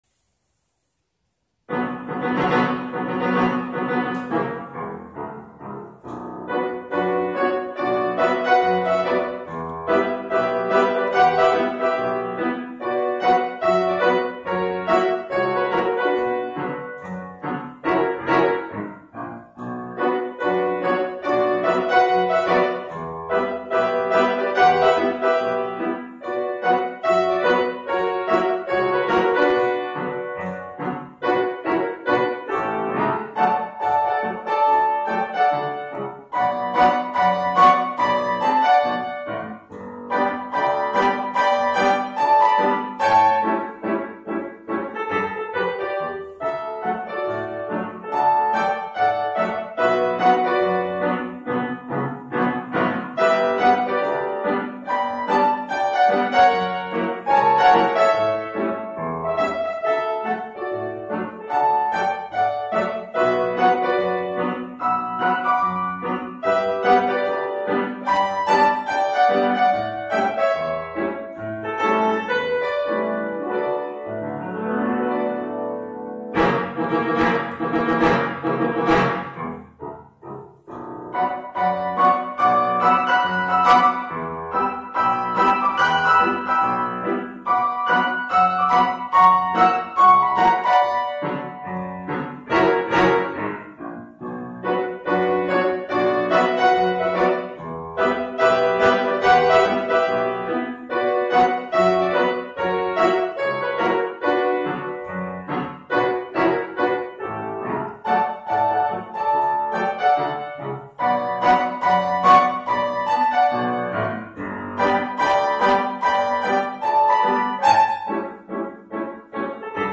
A continuación, la música del ending en versión piano.